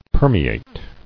[per·me·ate]